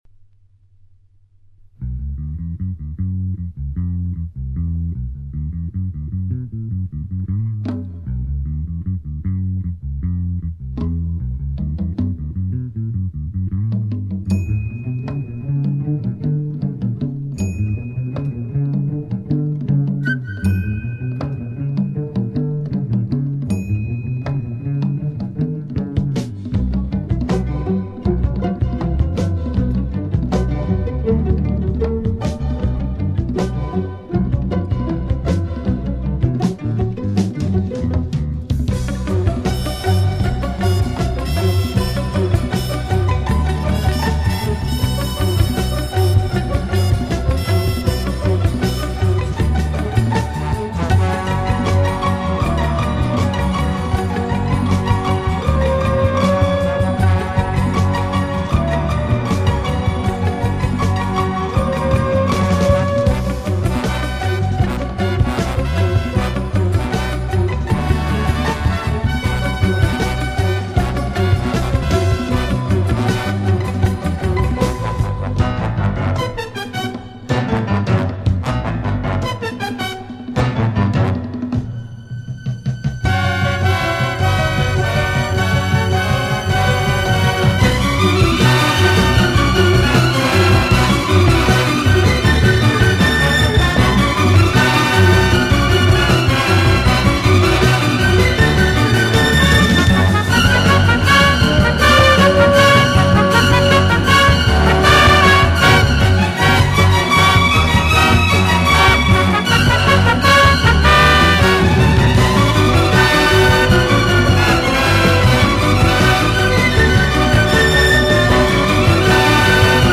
Genre:Exotica